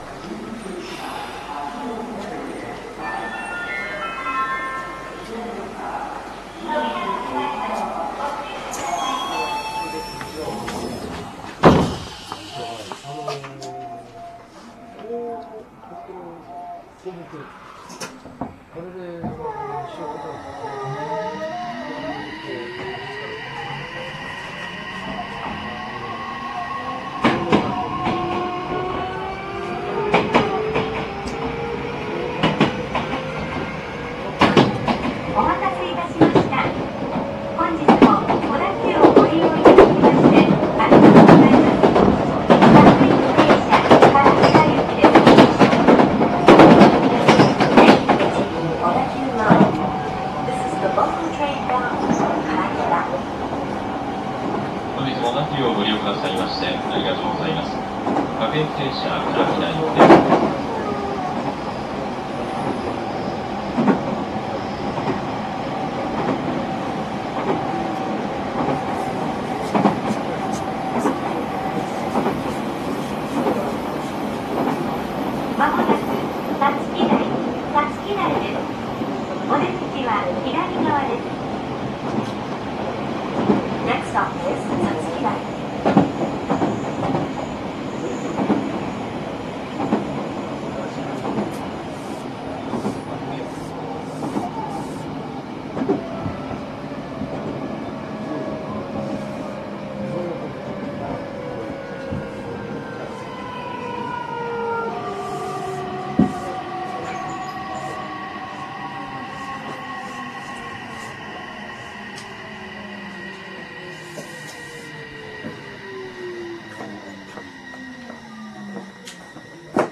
この影響からか変調音にも差が現れ、起動時のモーターの唸りが大きくなったほか、非同期から同期に移る部分がスムーズになっています。
走行音（3265F・3215）
収録区間：多摩線 新百合ヶ丘→五月台